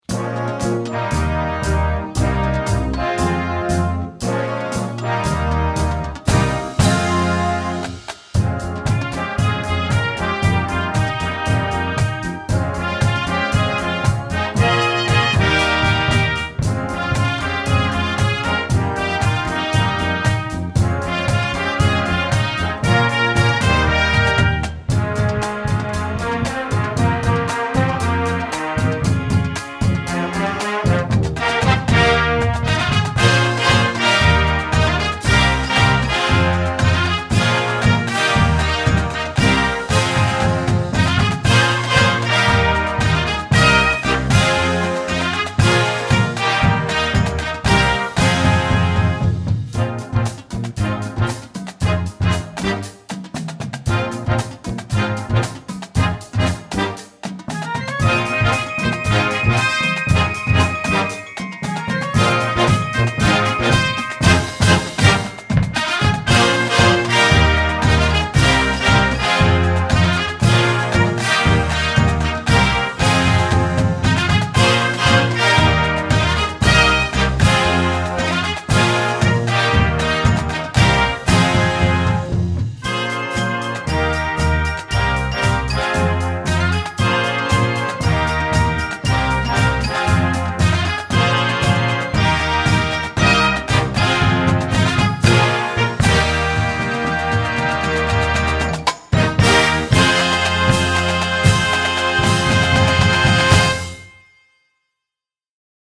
Gattung: Moderner Einzeltitel
Marching-Band
Besetzung: Blasorchester
energetic and familiar pop hit